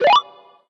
mortis_reload_02.ogg